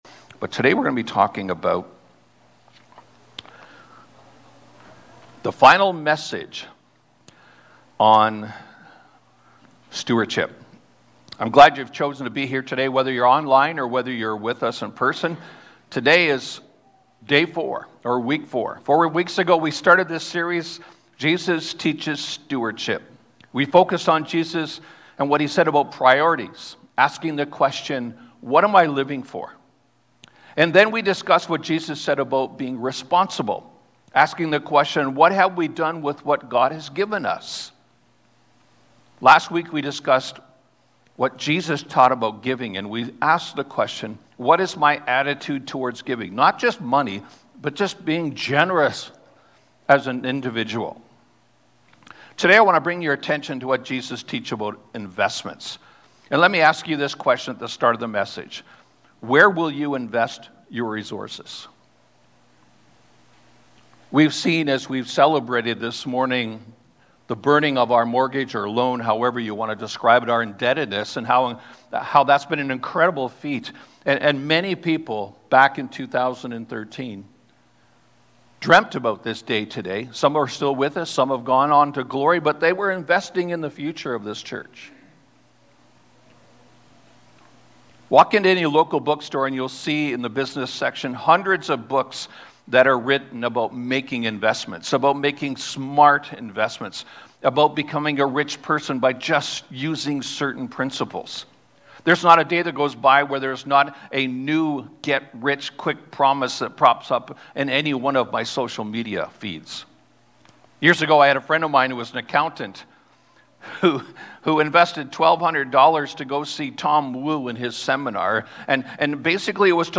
sermon series on Stewardship